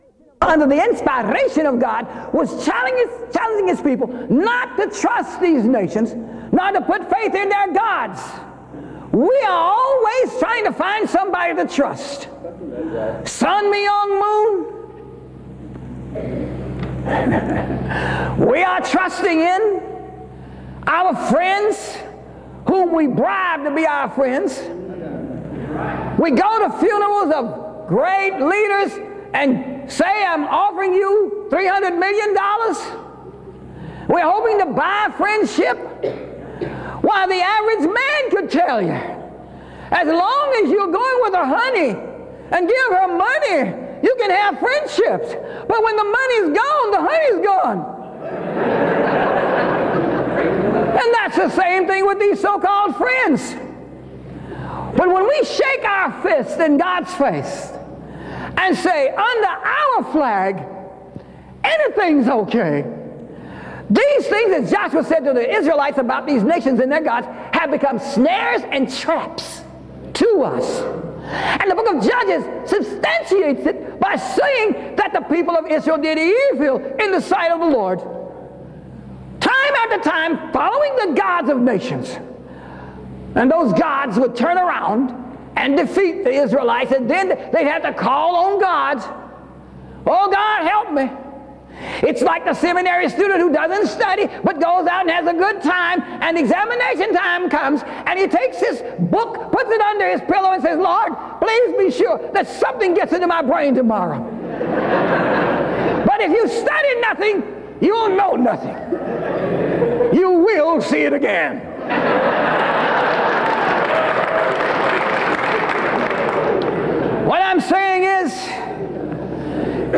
The service begins with two songs of worship and a word of prayer (00:00-08:17).
The speaker gives announcements about events happening at Southeastern (08:18-10:31). A representative from Doulos gives a testimony about sharing the gospel (10:32-16:00).
Evangelistic sermons--United States